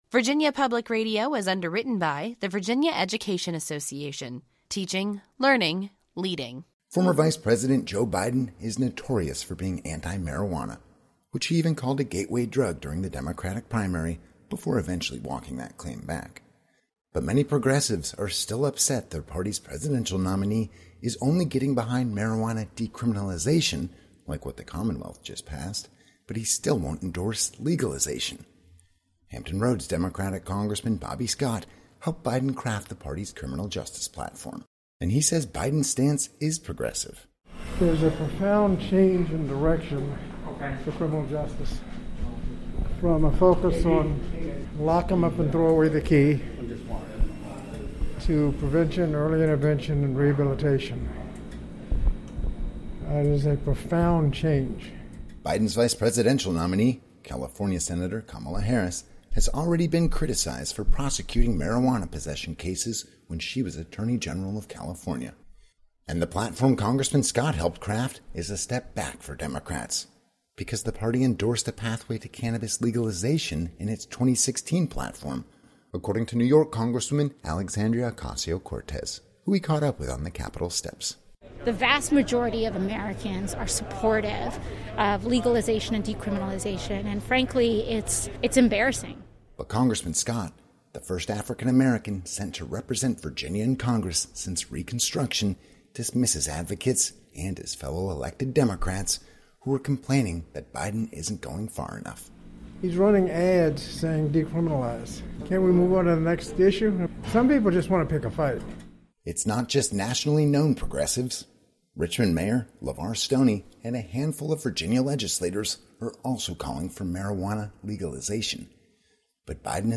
reports from Washington.